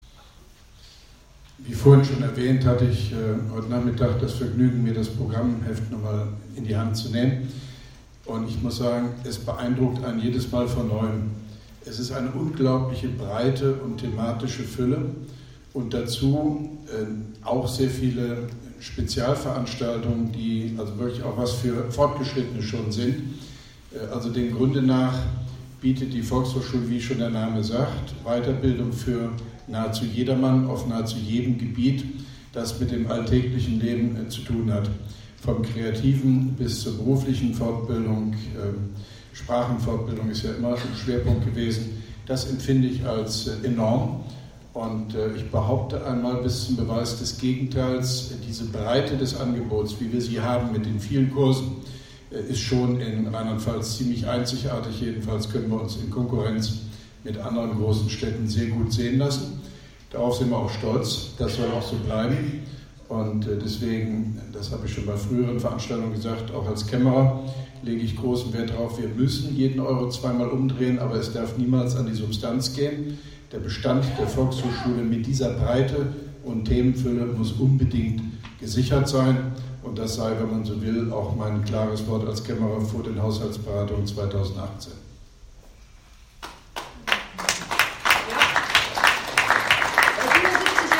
Grußwort von OB Hofmann-Göttig mit Begrüßungstalk anlässlich 70 Jahre Volkshochschule Koblenz, Koblenz 15.09.2017